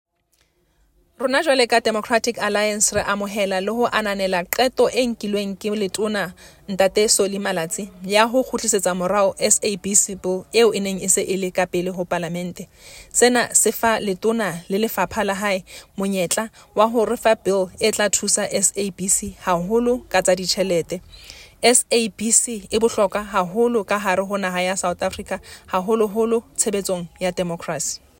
Sesotho soundbite by Thsolofelo Bodlani MP.